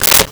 Switchboard Telephone Receiver Put Down 02
Switchboard Telephone Receiver Put Down 02.wav